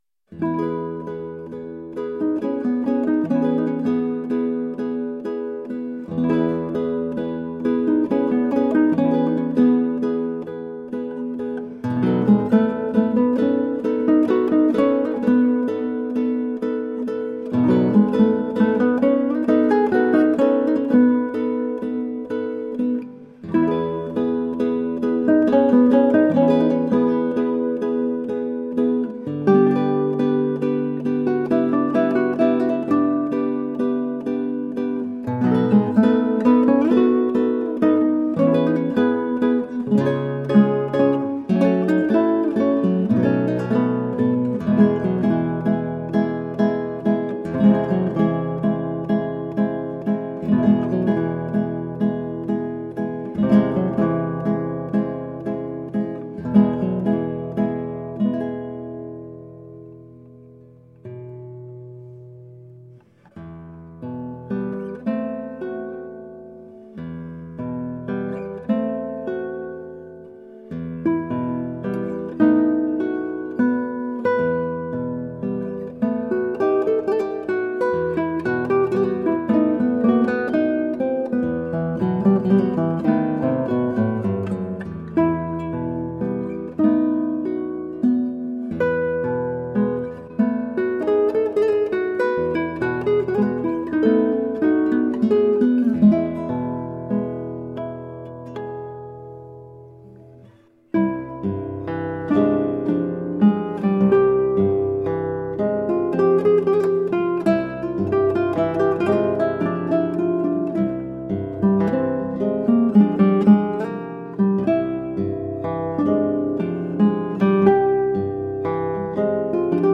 Colorful classical guitar.
It is highly expressive and gorgeously sensuous.
Instrumental
Classical Guitar